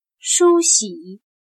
梳洗 / Shūxǐ /Peinarse